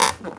chair_frame_metal_creak_squeak_03.wav